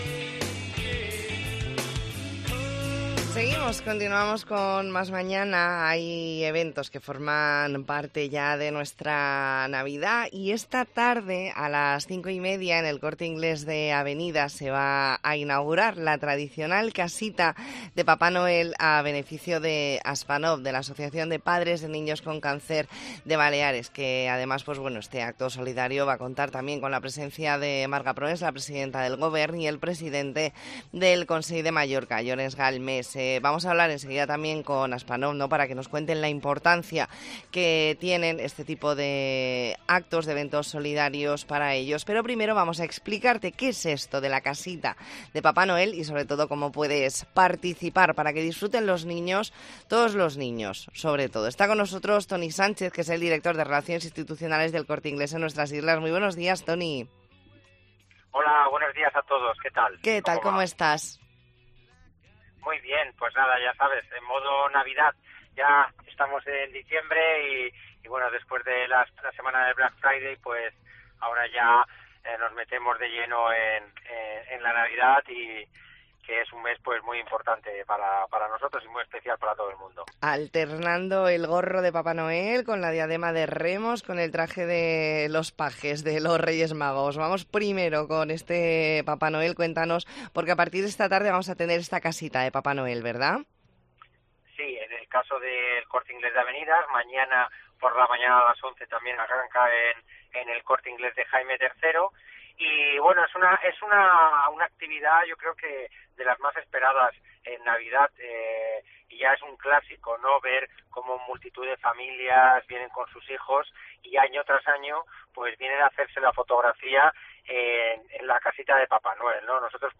Entrevista en La Mañana en COPE Más Mallorca, martes 5 de diciembre de 2023.